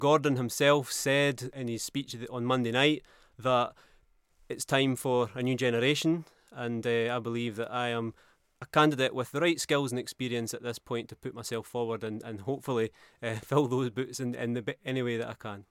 Councillor Selbie is telling us why he's chosen to stand: